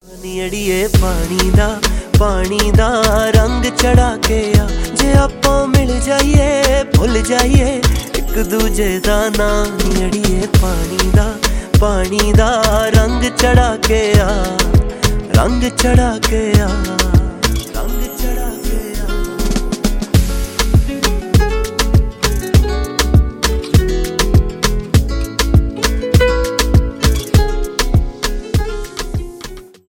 романтические
поп